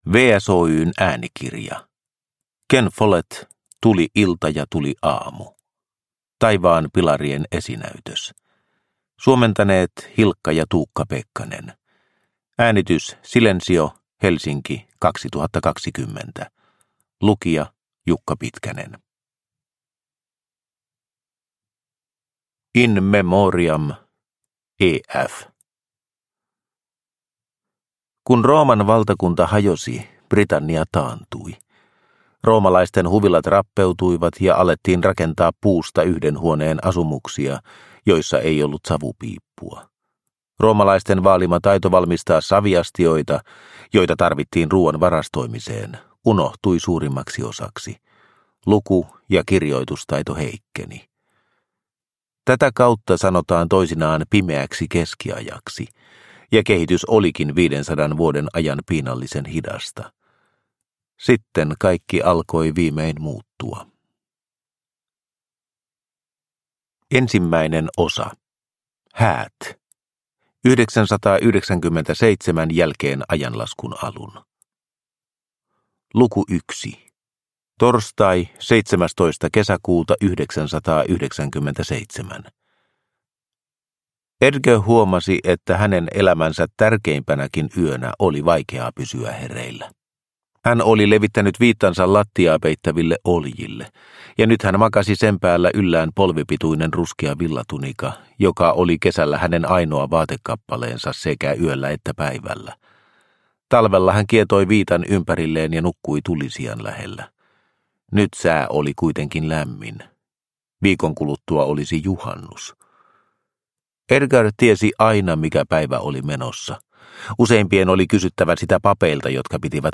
Tuli ilta ja tuli aamu – Ljudbok – Laddas ner